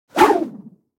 دانلود آهنگ باد 36 از افکت صوتی طبیعت و محیط
دانلود صدای باد 36 از ساعد نیوز با لینک مستقیم و کیفیت بالا
جلوه های صوتی